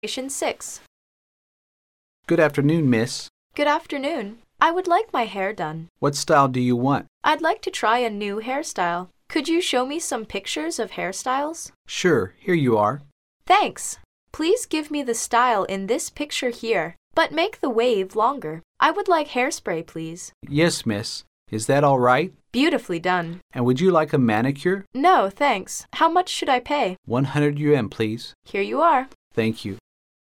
Conversation 6